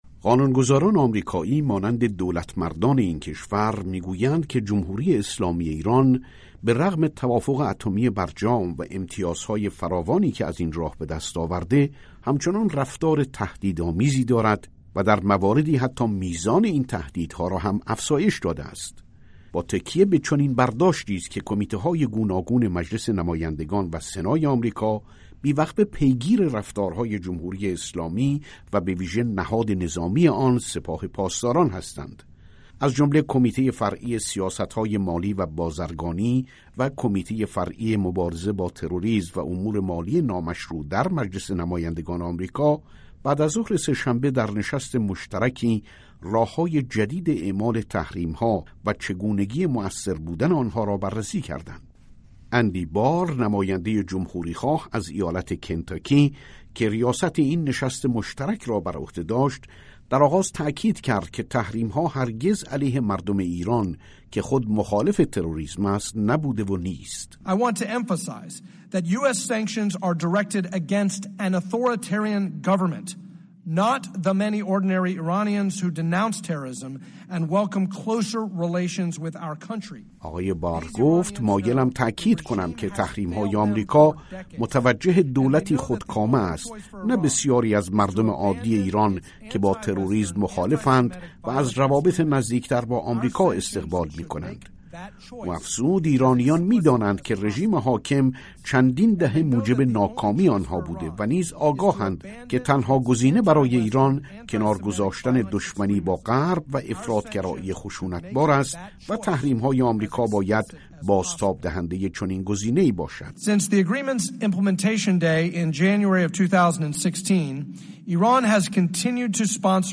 گزارش رادیویی درباره نشست دو کمیته فرعی مجلس نمایندگان آمریکا درباره تحریم‌های ایران